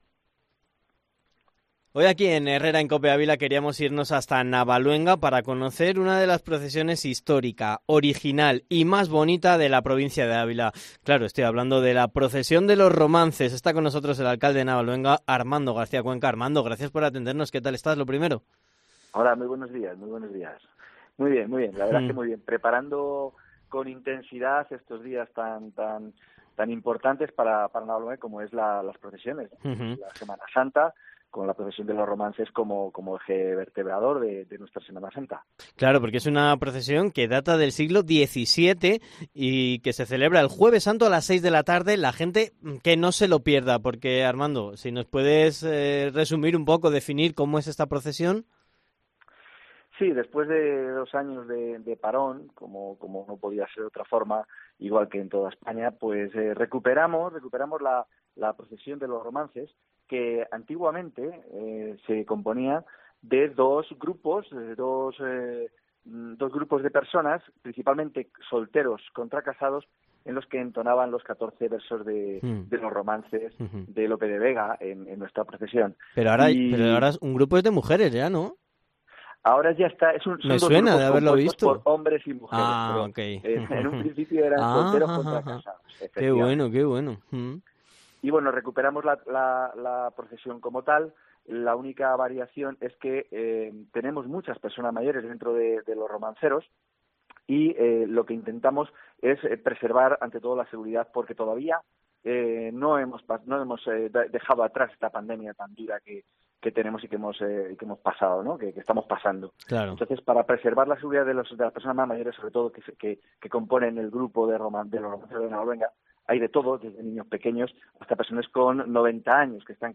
ENTREVISTA / El alcalde de Navaluenga, Armando García Cuenca sobre la Semana Santa
ENTREVISTA / El alcalde de Navaluenga, Armando García Cuenca, ha contado en COPE Ávila cómo será la Semana Santa en Navaluenga, donde destaca la procesión de los Romances de Interés Turístico Regional.